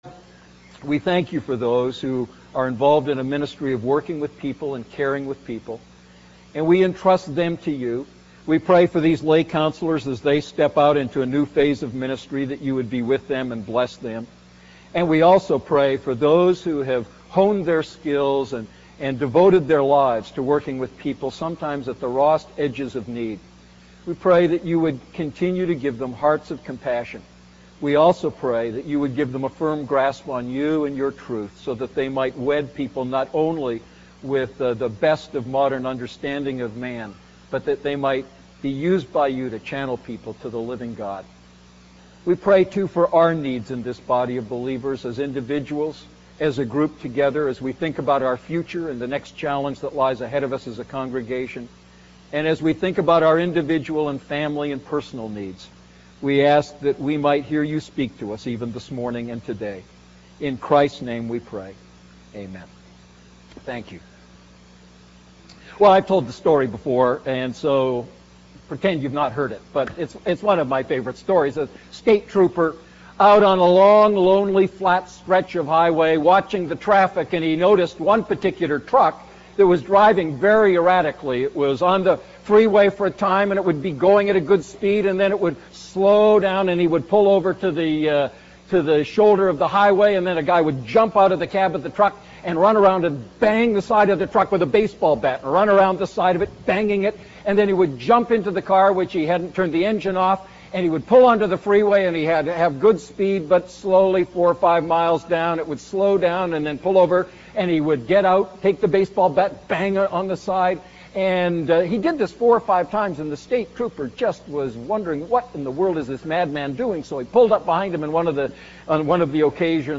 A message from the series "Family Matters."